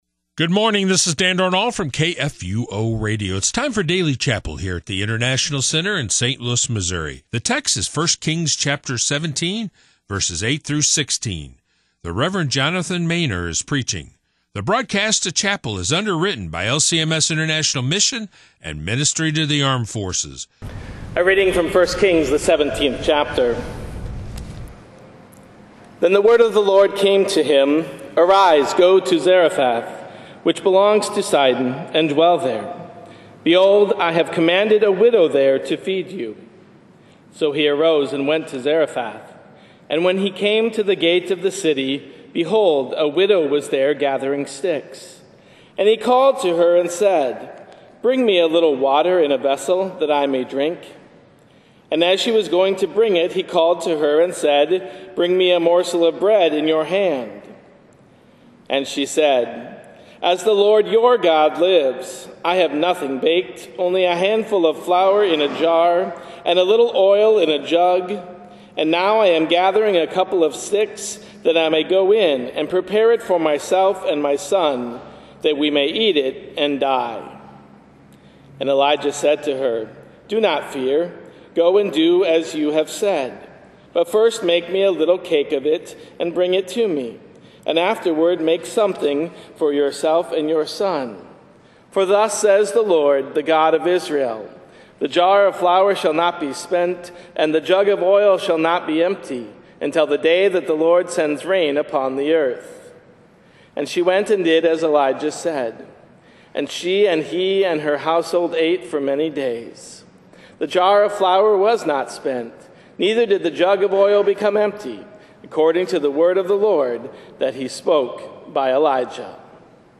>> The broadcast of chapel services is brought to you by LCMS International Mission and Ministry to Armed Forces.